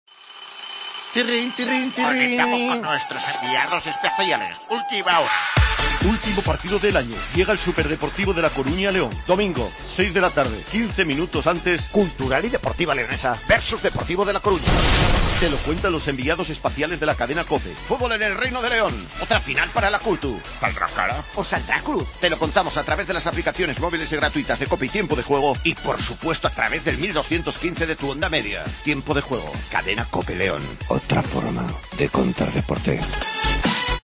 Escucha la cuña promocional del partido Cultural-Deportivo Coruña el día 19-12-21 a las 18:00 h en el 1.215 OM